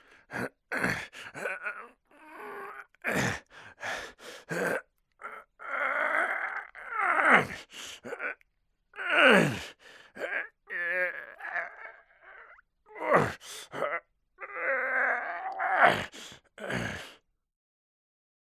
Tiếng Rặn của người đàn ông
Thể loại: Tiếng con người
Description: Còn có thể gọi là tiếng gầm, tiếng rên, tiếng hự, tiếng gắng sức, tiếng thở hổn hển hay tiếng gồng mình. Đây là loại âm thanh đặc trưng khi một người nam giới dùng toàn lực, thường phát ra trong lúc nâng vật nặng, tập thể hình, tập gym, hoặc chịu đựng áp lực mạnh mẽ...
Tieng-ran-cua-nguoi-dan-ong-www_tiengdong_com.mp3